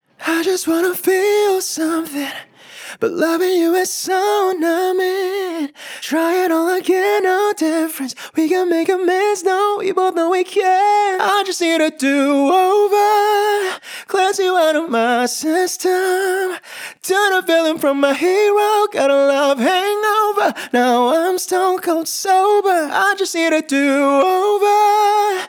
Zedd-HQ-POP-RAW-Vocal.wav